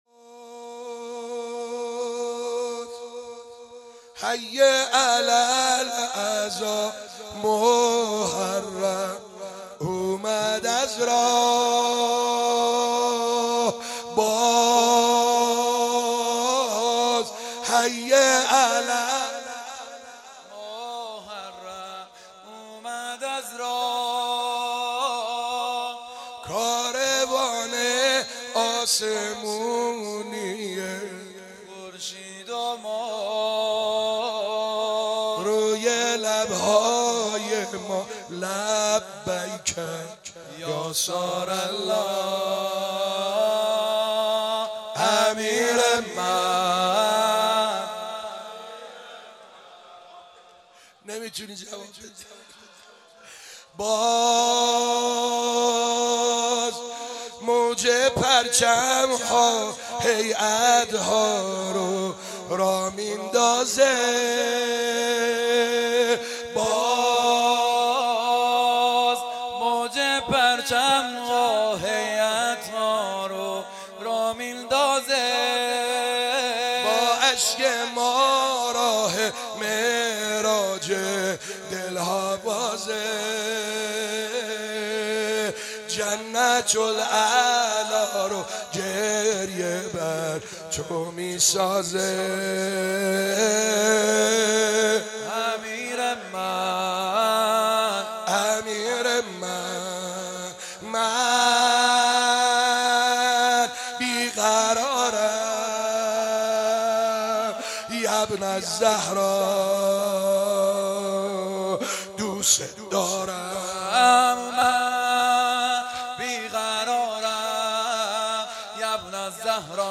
زمینه ، سال 94،جدید